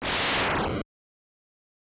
Contra SFX (13).wav